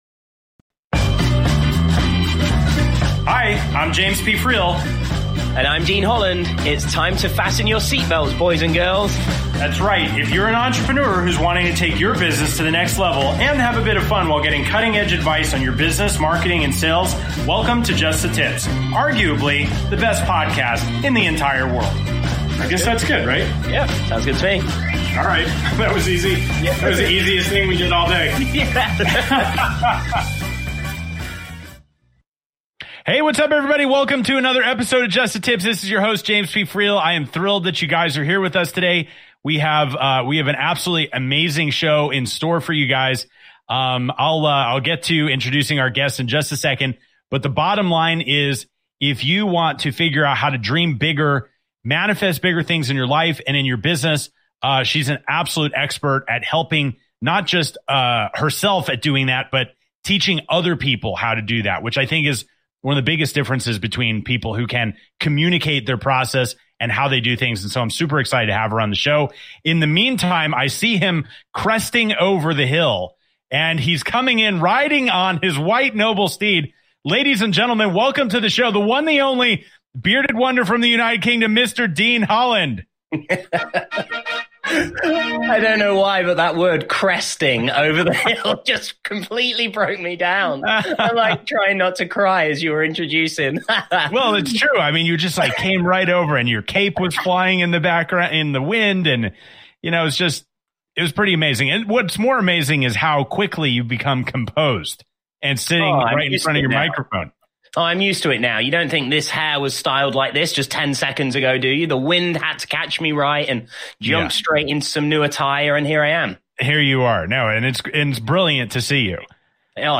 Talk Show
Just The Tips is a business talk show for entrepreneurs and business owners who are tired of listening to the same old stodgy content. Interviewing (and sometimes roasting) top entrepreneurs from around the world, each episode is fun, witty and informative.